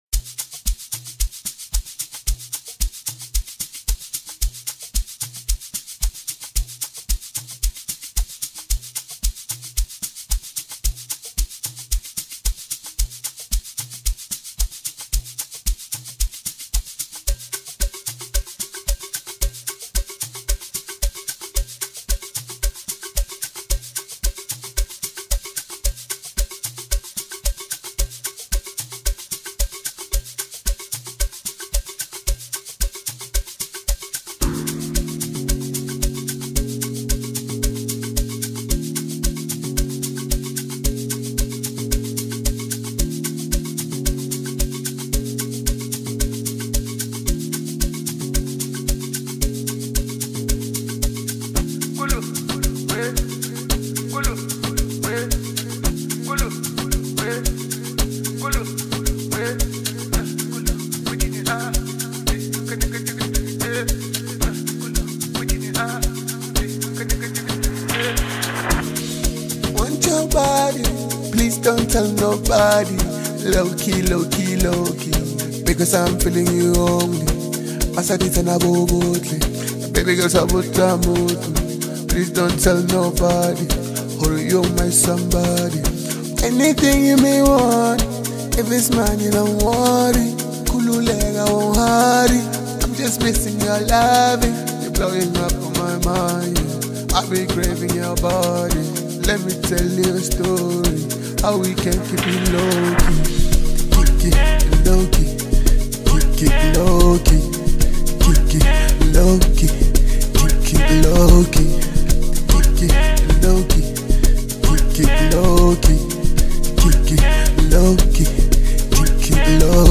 banging new track